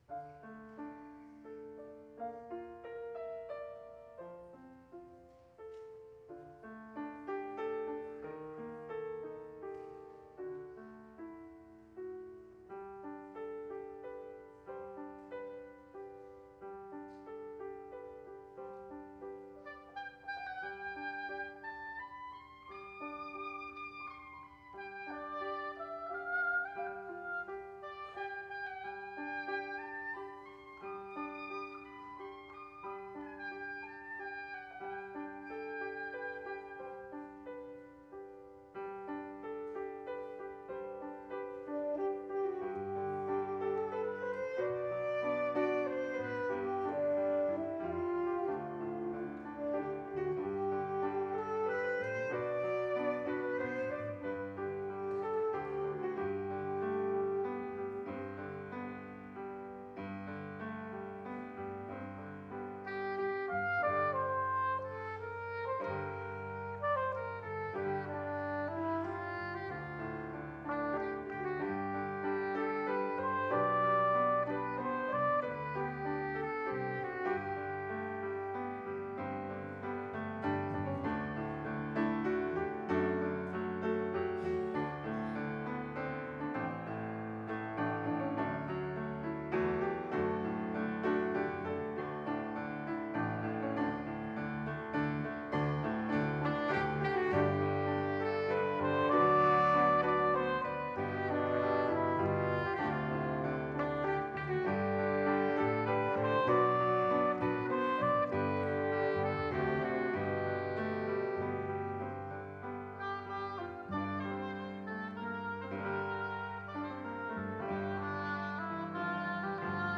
Index of /desk_top/dropbox/2022_chior_concert